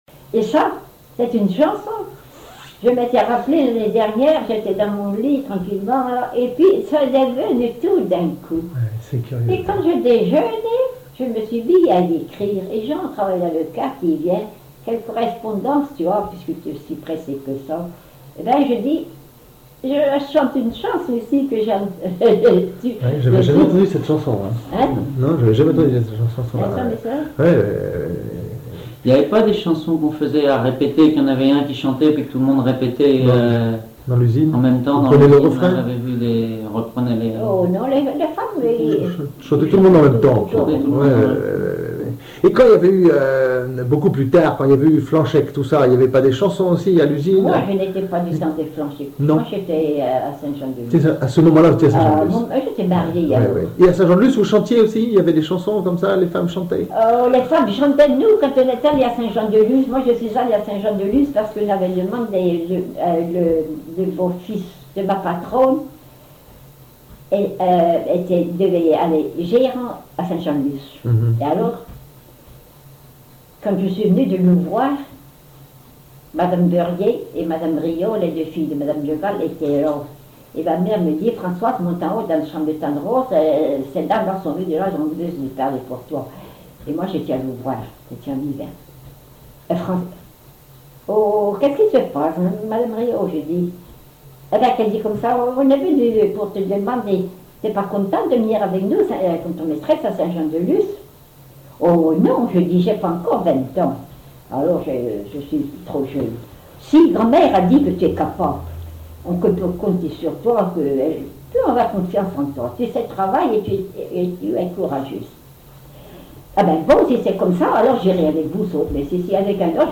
conserverie, boucane ; chanteur(s), chant, chanson, chansonnette
Catégorie Témoignage